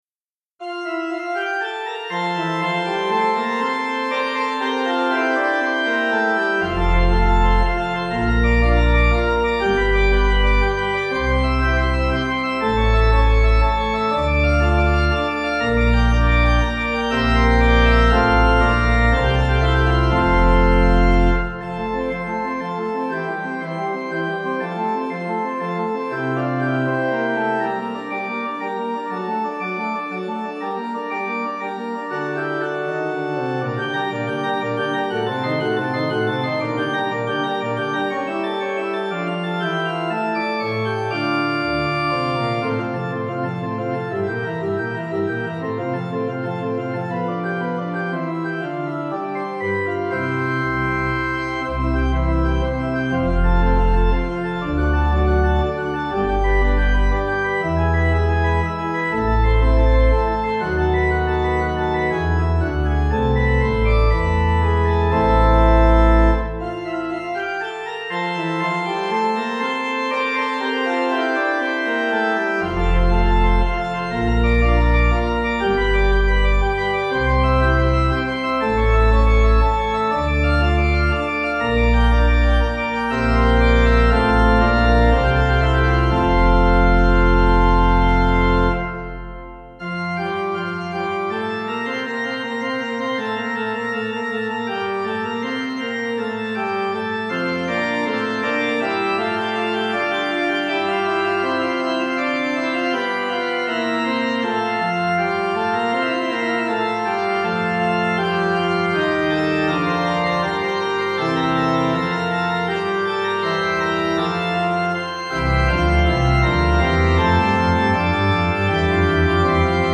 Organ
Easy Listening   F